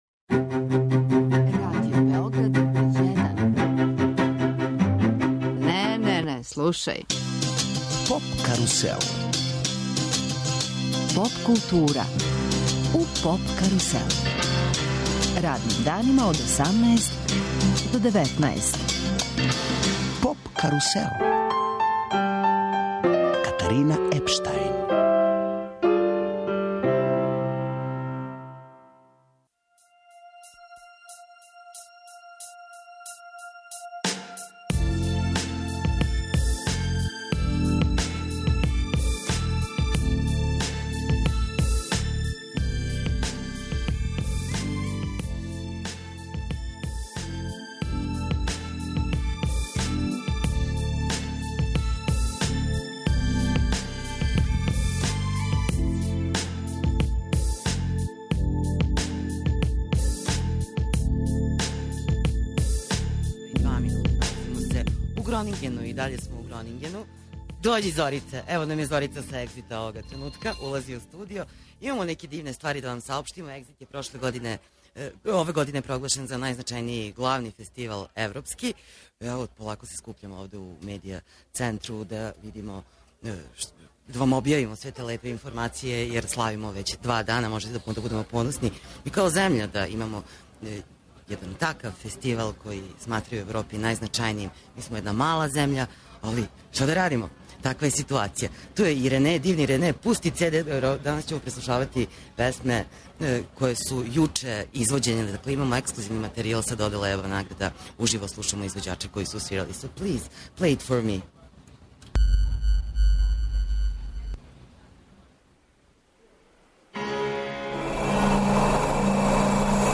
Радио Београд 1 и ове године уживо реализује емисије са фестивала Eurosonic, из Холандије. Eurosonic Noorderslag је музички фестивал посвећен изградњи европске поп сцене.